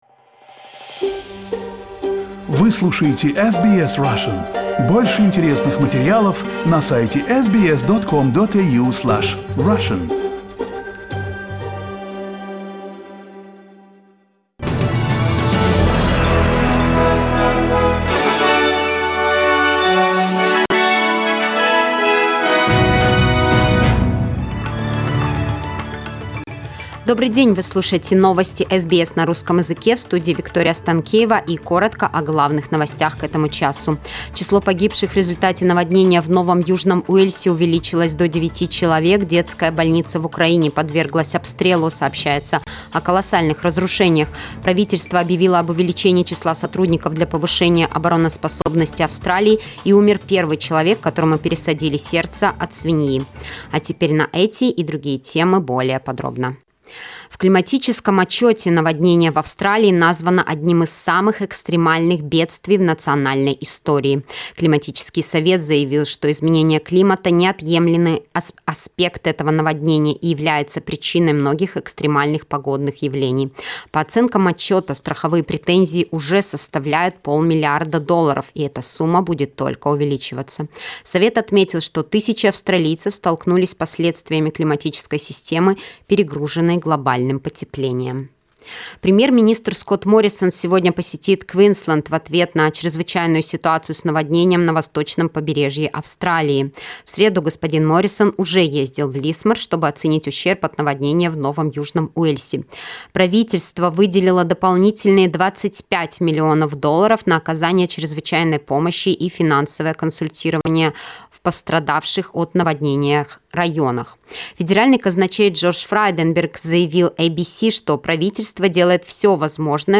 SBS news in Russian - 10.03